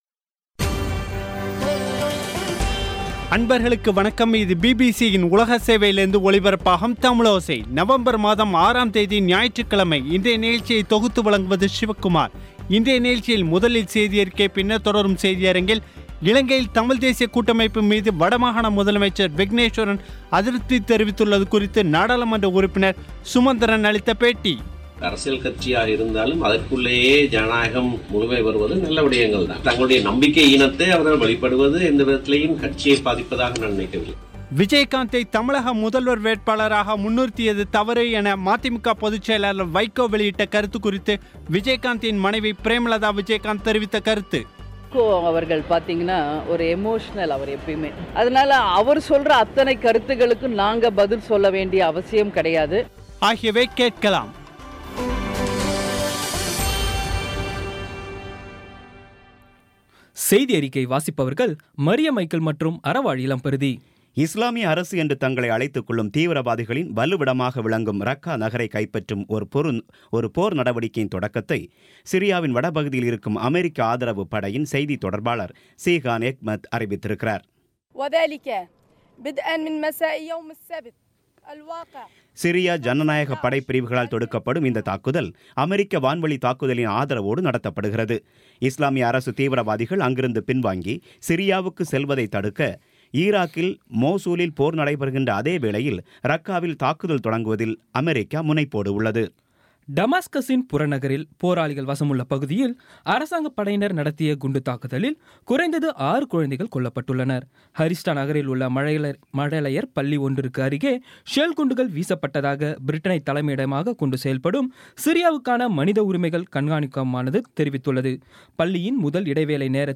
இன்றைய நிகழ்ச்சியில் முதலில் செய்தியறிக்கை, பின்னர் தொடரும் செய்தியரங்கில்
இலங்கையில் தமிழ் தேசிய கூட்டமைப்பு மீது வடமாகாண முதல்வர் விக்னேஷ்வரன் அதிருப்தி தெரிவித்துள்ளது குறித்து நாடாளுமன்ற உறுப்பினர் எம்.ஏ. சுமந்திரன் அளித்த பேட்டி